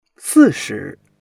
si4shi2.mp3